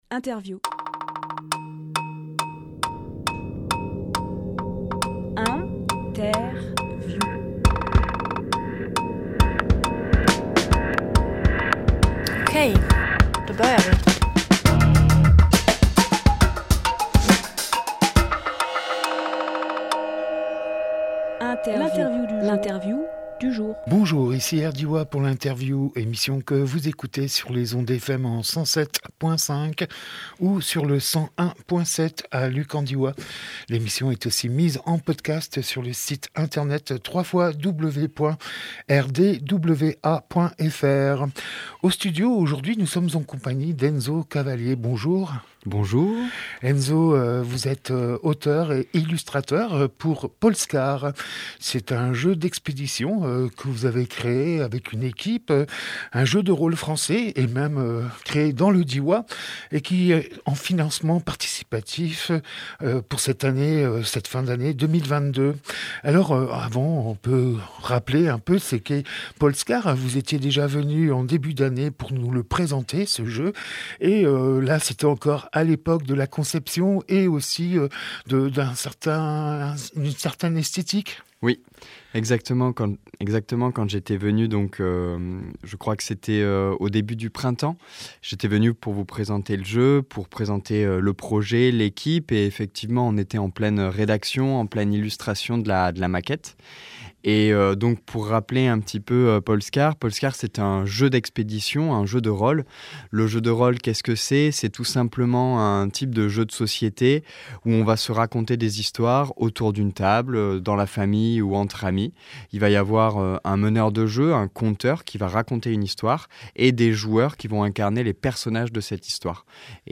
Emission - Interview Financement participatif pour Polskar Publié le 22 novembre 2022 Partager sur…
21.11.22 Lieu : Studio RDWA Durée